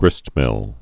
(grĭstmĭl)